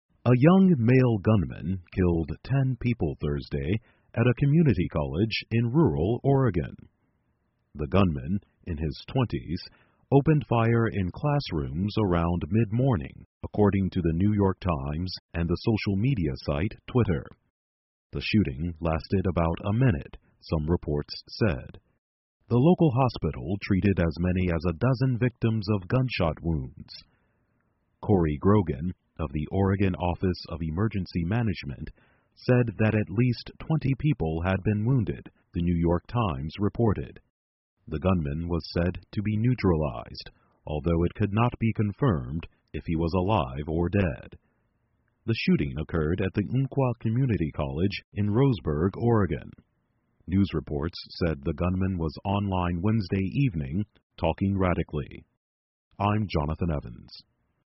在线英语听力室美国又发生一起枪击案的听力文件下载,2015年慢速英语(十)月-在线英语听力室